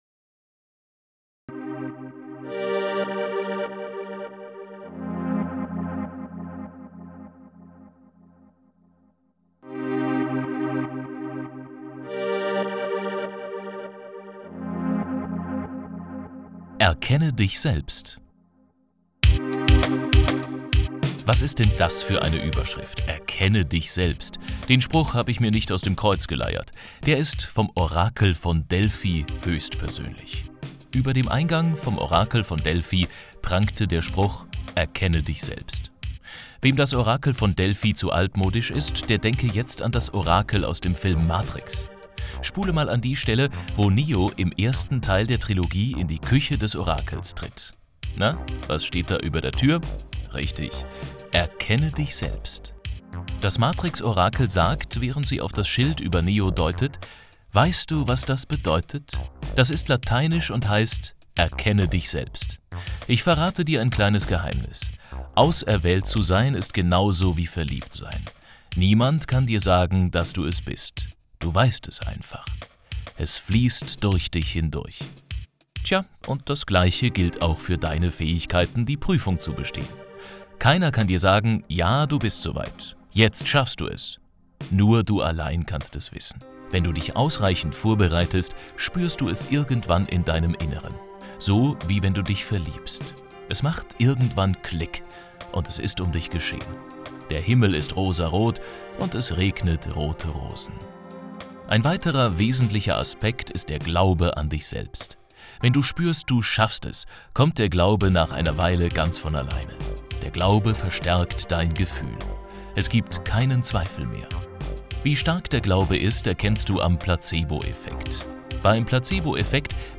Dein Bonus: MP3-Hörbuch „Zweifel überwinden – sicher zum IHK-Abschluss“ zum Download (